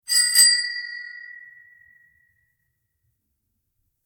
Bicycle Bell
Bicycle_bell.mp3